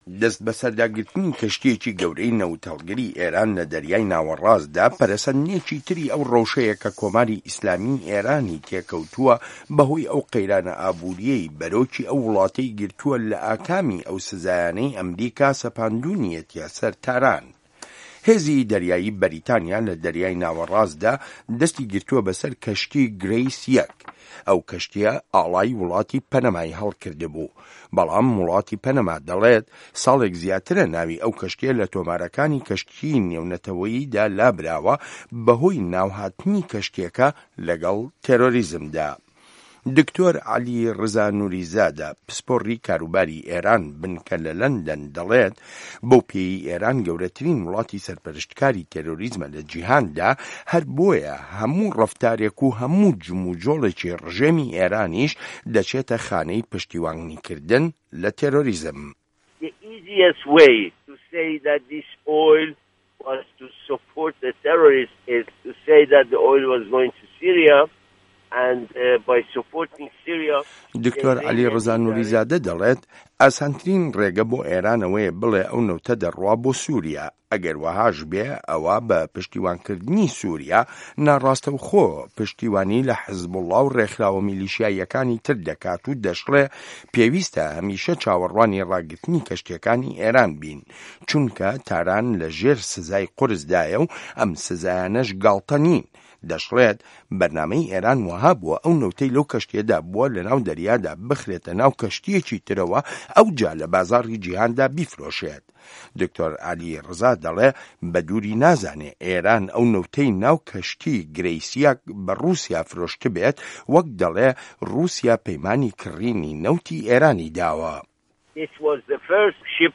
ڕاپۆرت لەسەر دەستبەسەرداگرتنی کەشتییە نەوت هەڵگرەکەی ئێران